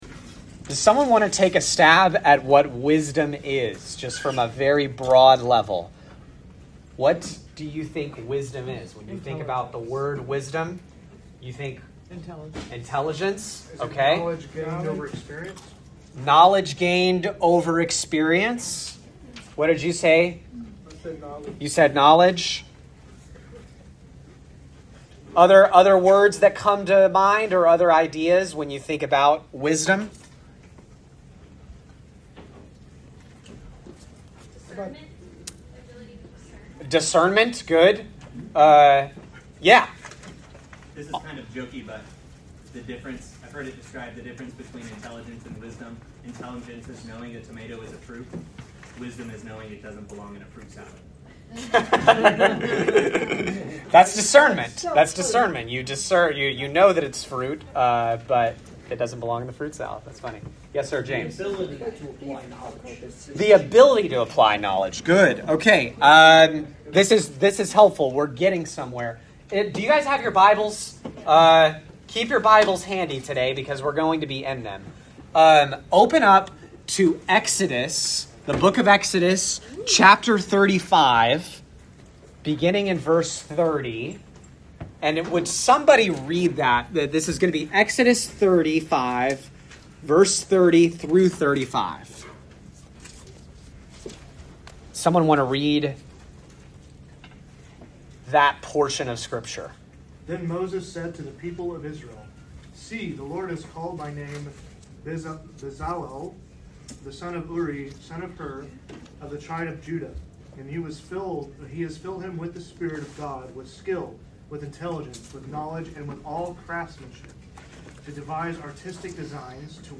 Equip Class: Opening the Scriptures